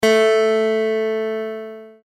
Use the audio tones below to tune your guitar to an Open D Tuning (commonly used for playing slide).
A String